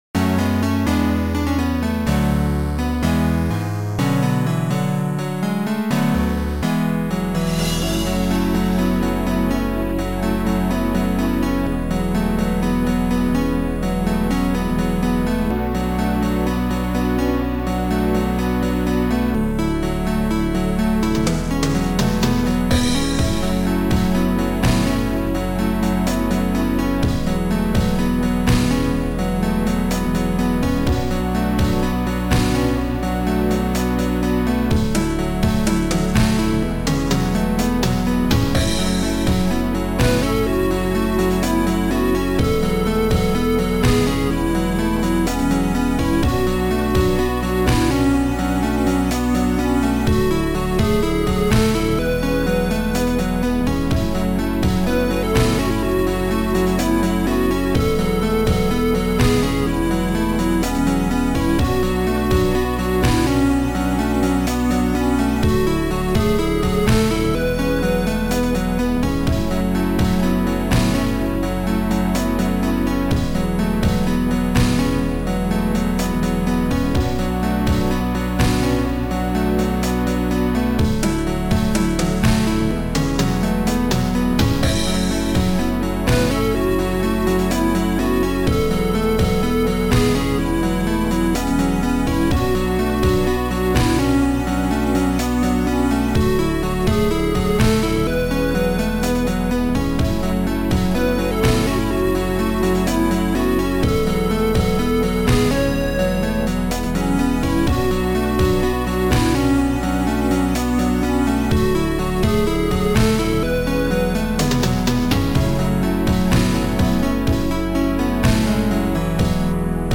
Sound Format: Noisetracker/Protracker
Sound Style: Mellow